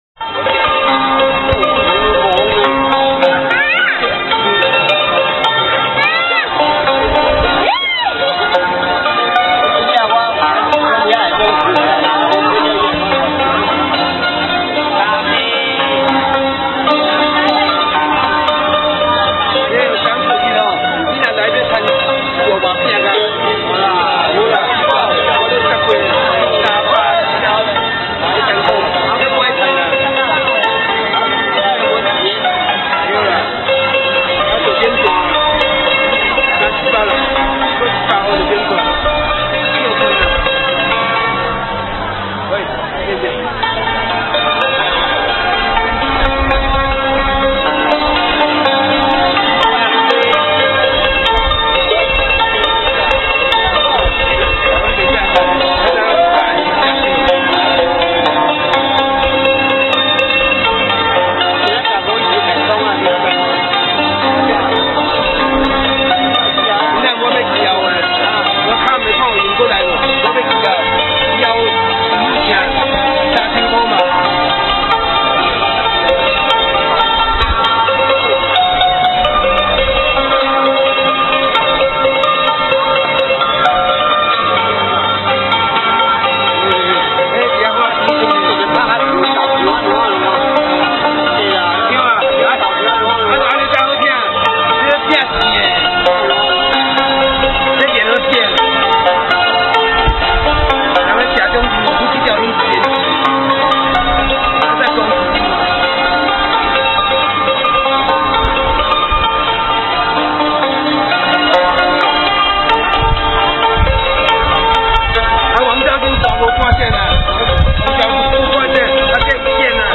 揚琴の演奏
yo-kin.mp3